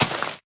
grass4.ogg